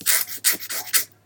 scratch.ogg